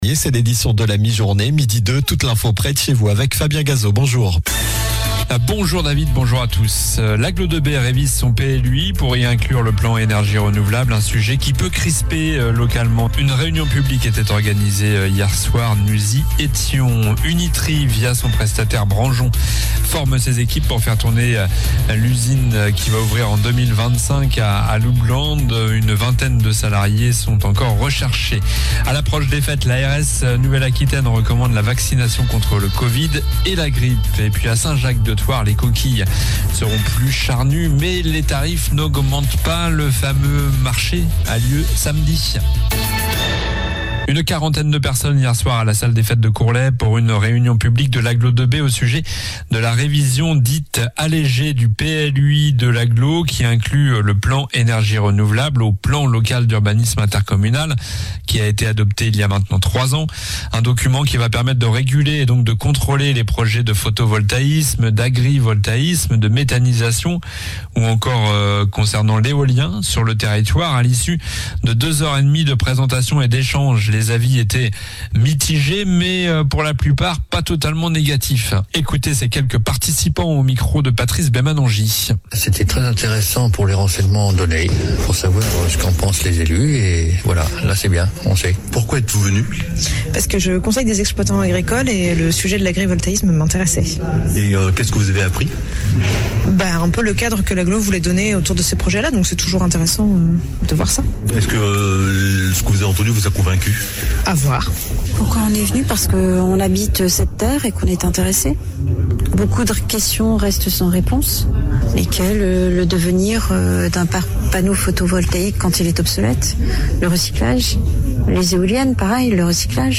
Journal du jeudi 12 décembre (midi)